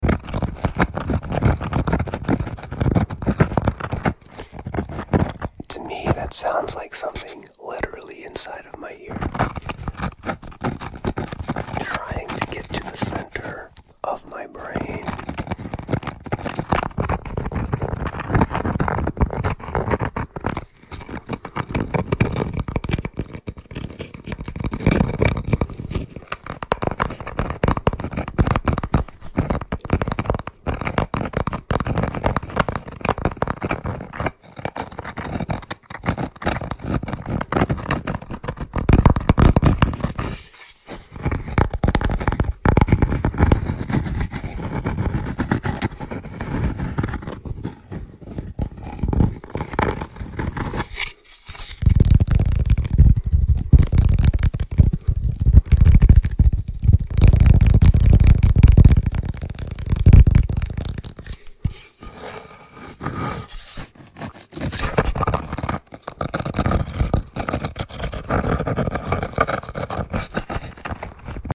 This simple ASMR sound will sound effects free download
This simple ASMR sound will scratch those deep brain itches 💯 ASMR scratching - ASMR wood tapping - Fast tapping - ASMR fast tapping